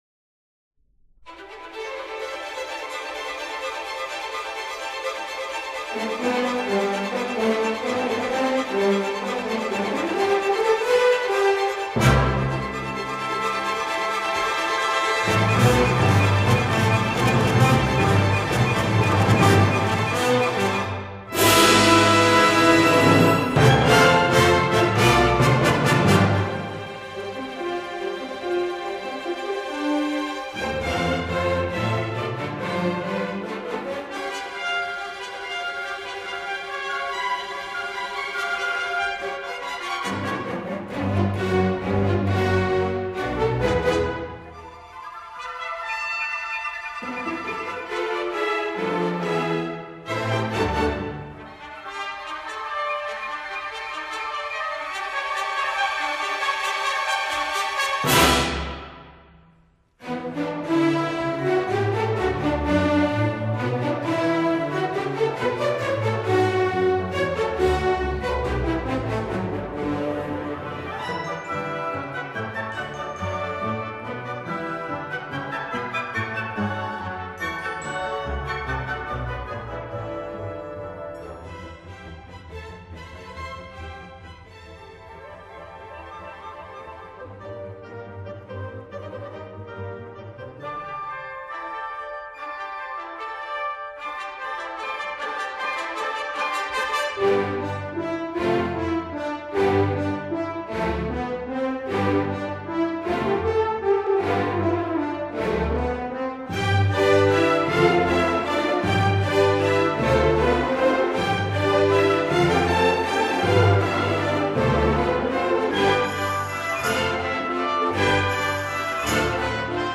第一部分气势异常浩荡，欢乐的情绪犹如一幕幕场景，此起彼落，绵亘不绝。